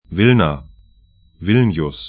Pronunciation
Wilna 'vɪlna Vilnius 'vɪlnĭʊs lt Stadt / town 54°41'N, 25°19'E